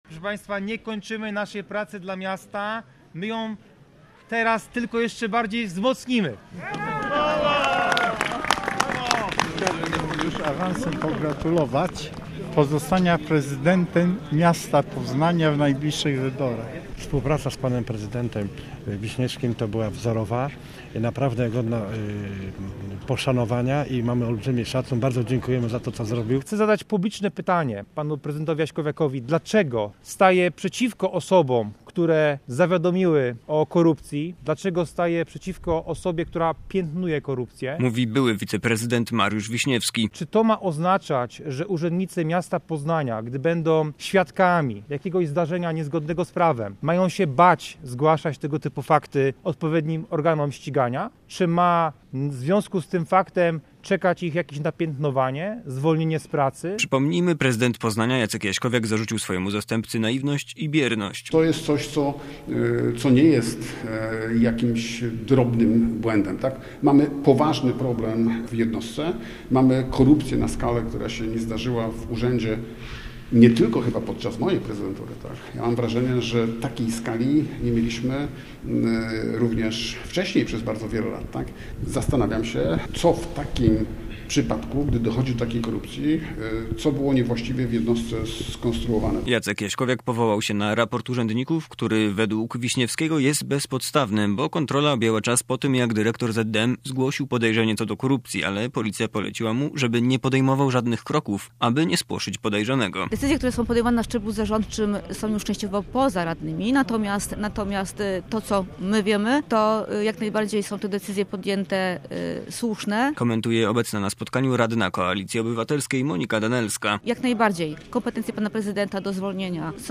Mariusz Wiśniewski podczas spotkania z mediami powiedział, że został zwolniony z funkcji wiceprezydenta, ponieważ środowisko polityczne prezydenta Jacka Jaśkowiaka obawiało się o swoją przyszłość.